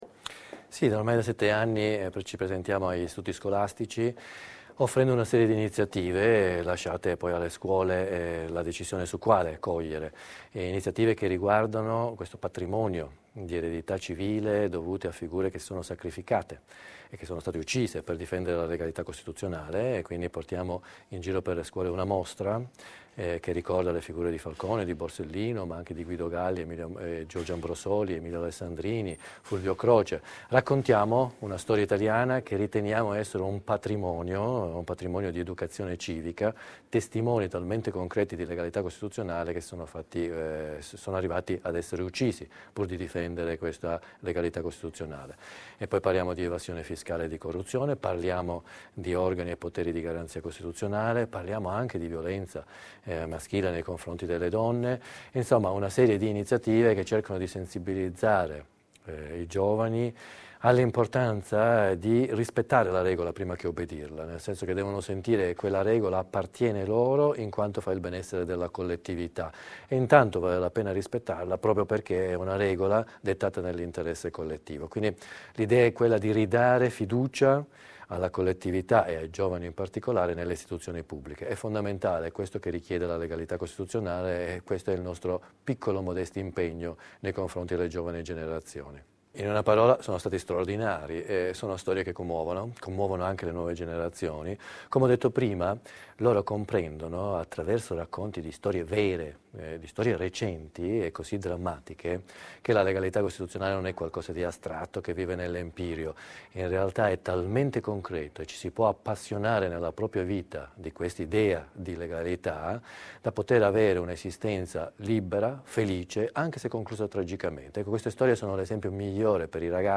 Durante l’inaugurazione avvenuta ieri, alla presenza degli studenti e dei responsabili regionali dell’ANSA, il giudice Pasquale Profiti, rappresentante dell’Associazione Nazionale Magistrati, ha ripercorso le tappe della vita dei due magistrati legati dalla passione per la legalità costituzionale. Profiti ha spiegato attraverso la testimonianza di Falcone e Borsellino l’importanza del rispetto delle regole in quanto fonte di benessere per la collettività.